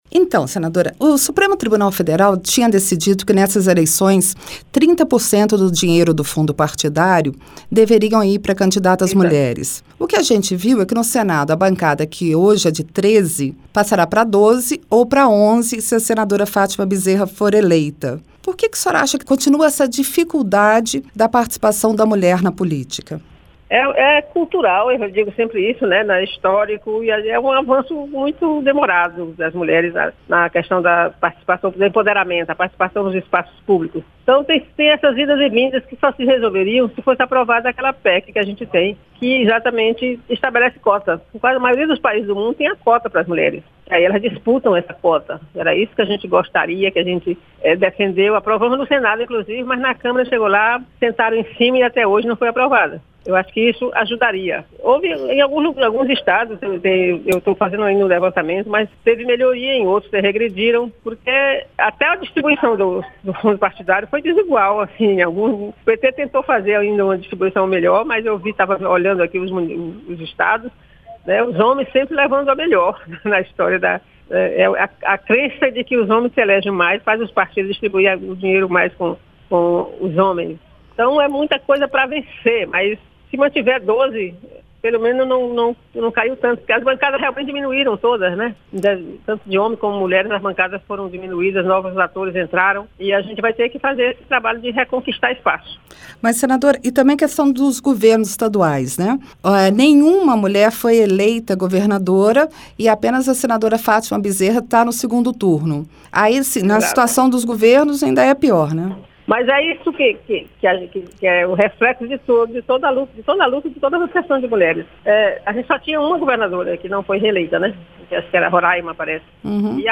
A senadora, que foi eleita domingo vice-governadora do Piauí, conversou com a jornalista